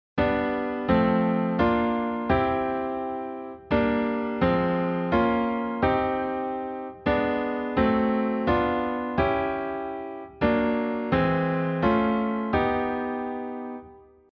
Because each chord formation will give you a different feel while using this technique, here is a comparison while using the same melodic line represented in red